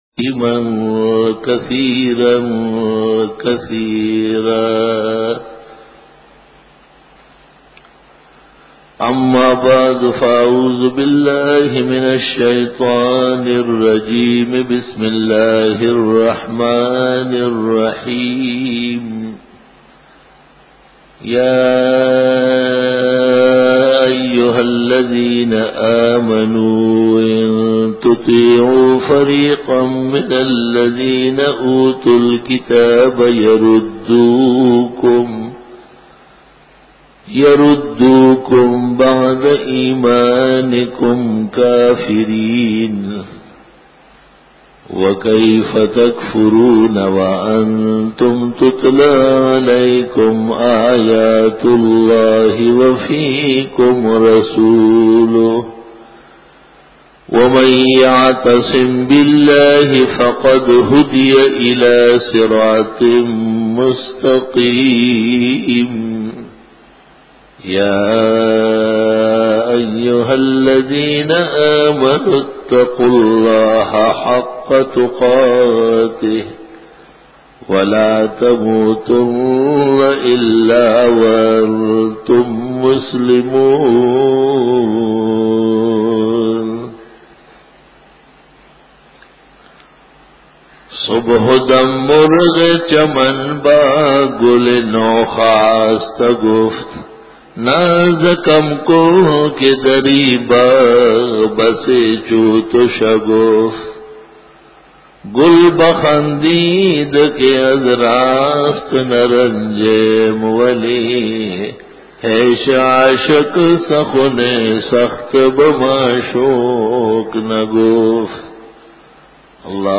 010_Jummah_Bayan_08_Mar_2002
بیان جمعۃ المبارک